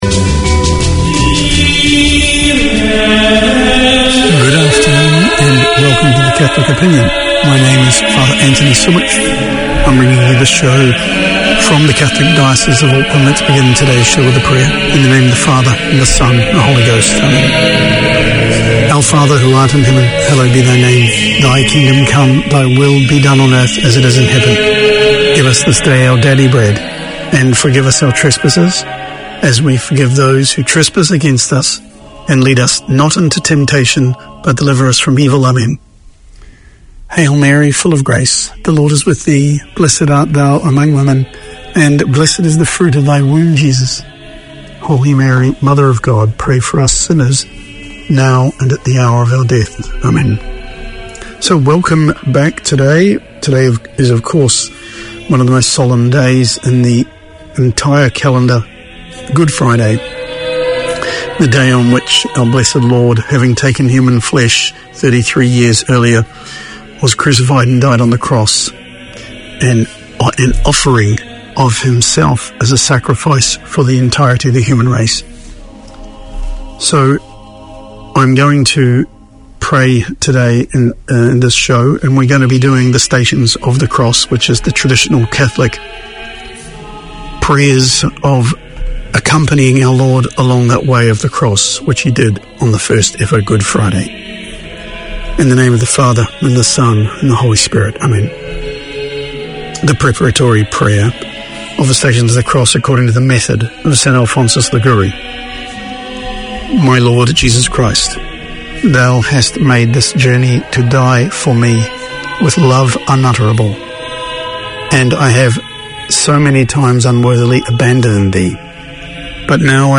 An outreach of the Pacific Islands Health and Welfare project under the auspices of the Auckland Health Board, Cook Islands Health is the half hour each week that keeps you in touch with health news, with interviews, information, community updates.